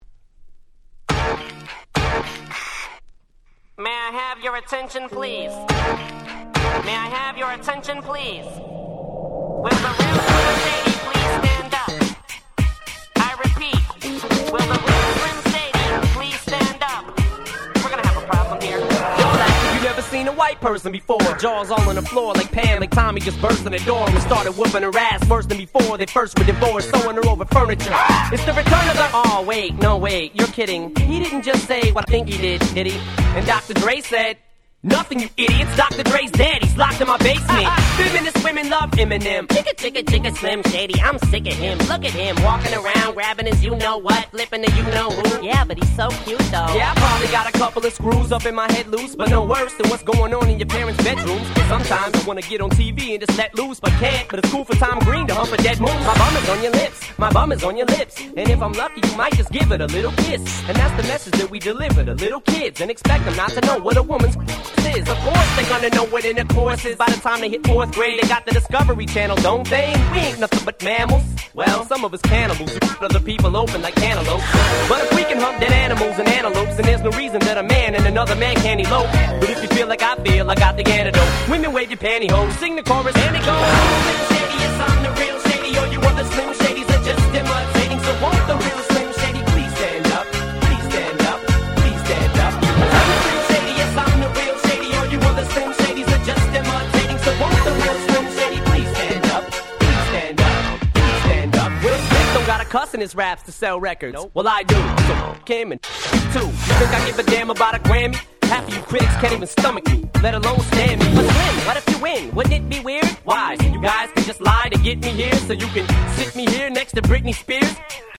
White Press Only Mash Ups !!